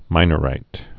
(mīnə-rīt)